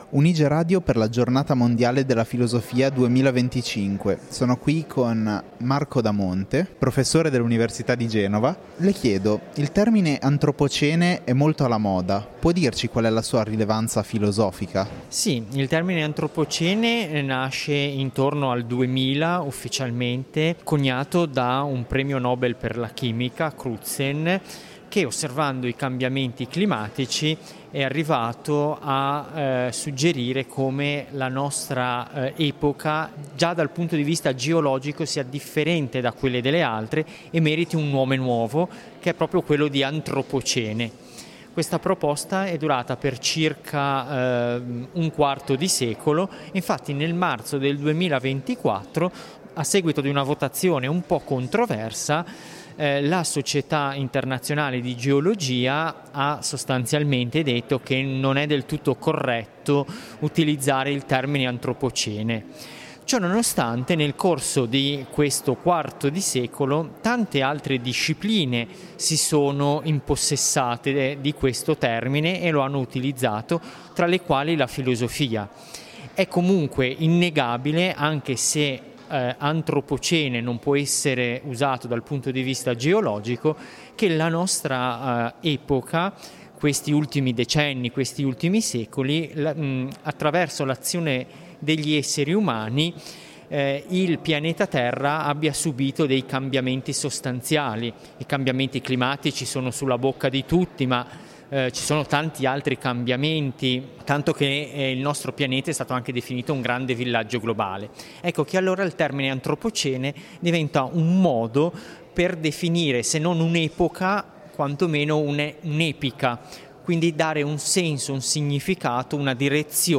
Un’intervista per pensare il presente e il futuro del pianeta.